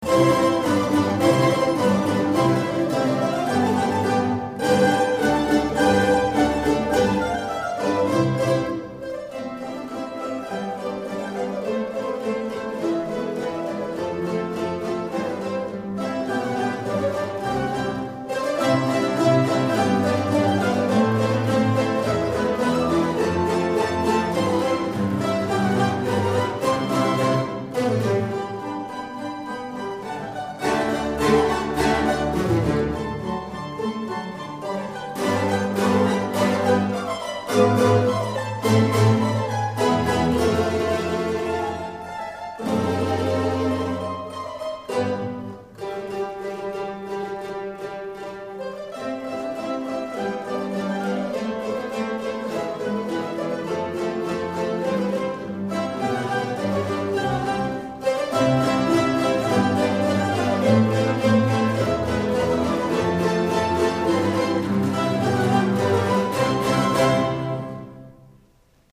駒ヶ根マンドリーノ　第２７回定期演奏会
会　場　　　　　駒ヶ根市文化会館　大ホール